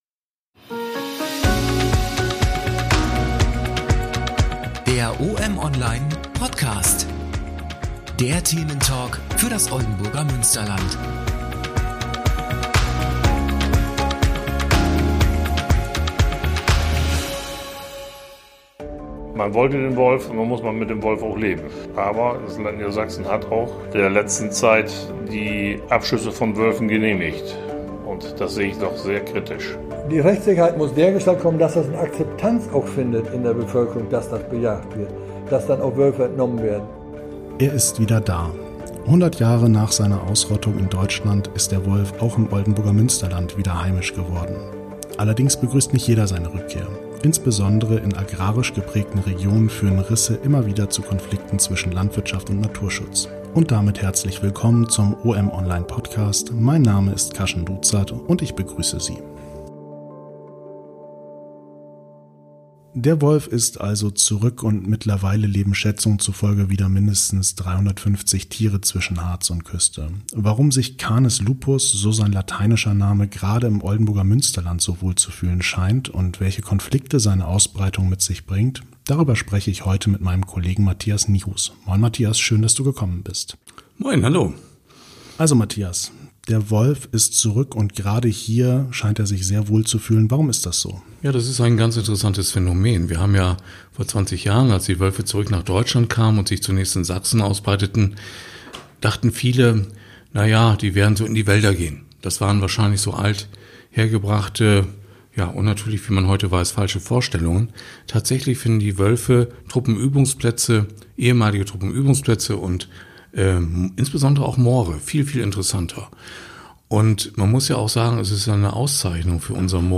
Der Thementalk für das Oldenburger Münsterland: In der 3.